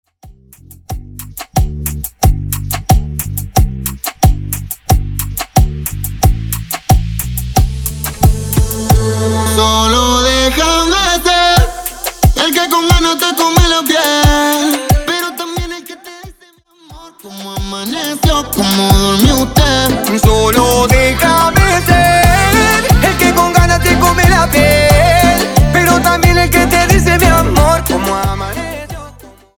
Extended Dirty Intro Acapella